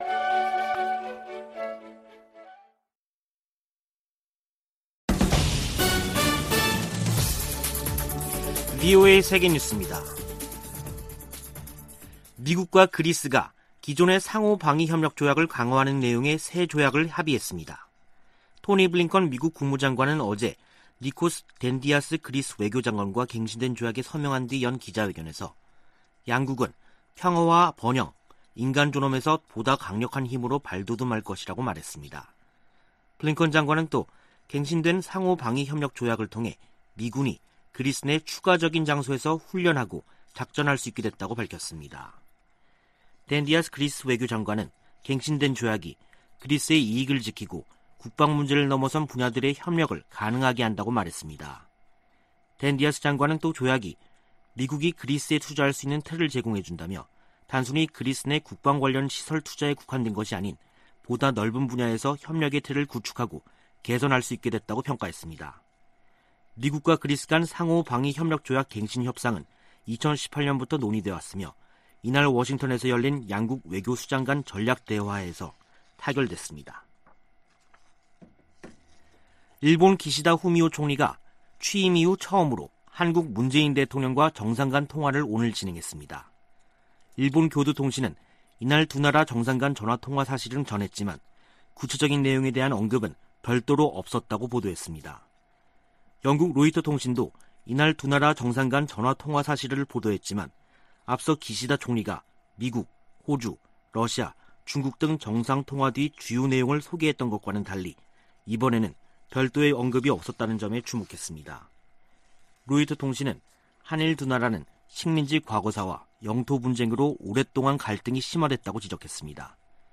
VOA 한국어 간판 뉴스 프로그램 '뉴스 투데이', 2021년 10월 15일 2부 방송입니다. 미국이 탈퇴 3년 만에 유엔 인권이사회 이사국으로 선출됐습니다. 미 국무부가 북한 핵 문제 해결을 위해 동맹과 활발한 외교를 펼치고 있다고 밝혔습니다. 미국과 한국 등 30여개국이 세계적으로 증가하는 랜섬웨어 공격 대응에 적극 공조하기로 했습니다.